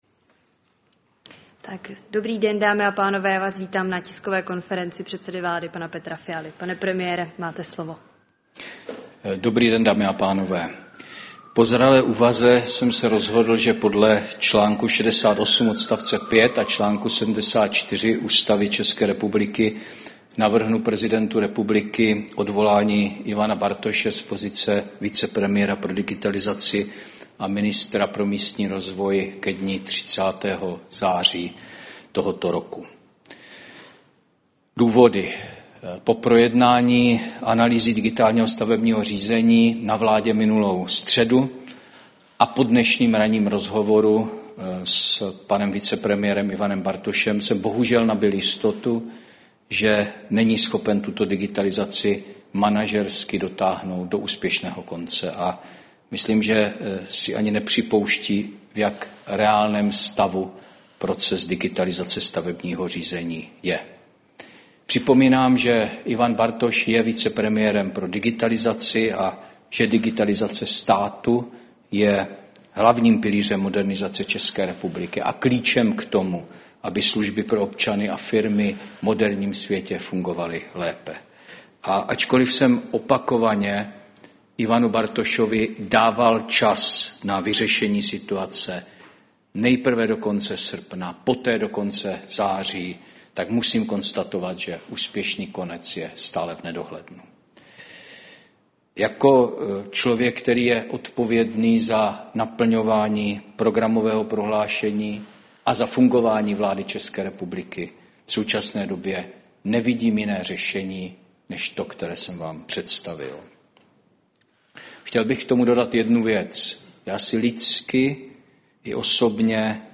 Tisková konference předsedy vlády k návrhu na odvolání vicepremiéra a ministra pro místní rozvoj Ivana Bartoše, 24. září 2024 | Vláda České republiky